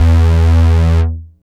72.08 BASS.wav